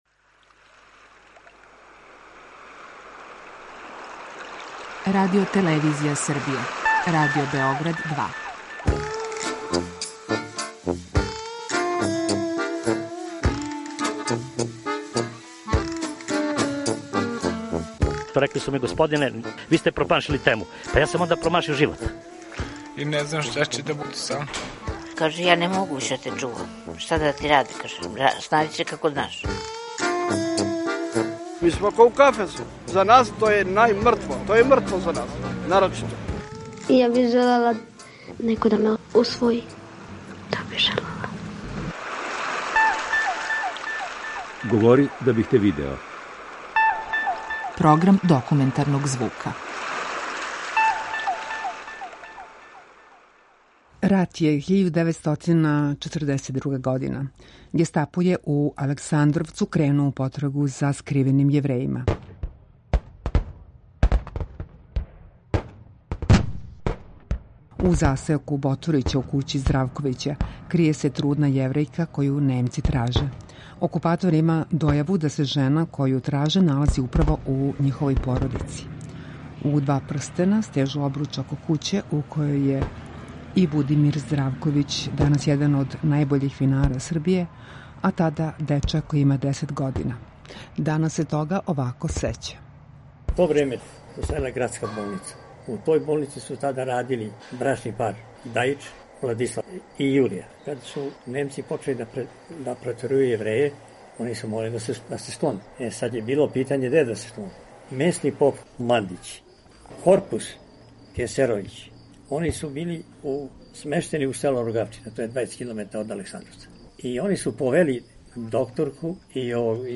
Гестапо је у Александровцу кренуо у потрагу за скриваним Јеврејима. преузми : 10.79 MB Говори да бих те видео Autor: Група аутора Серија полусатних документарних репортажа, за чији је скупни назив узета позната Сократова изрека: "Говори да бих те видео".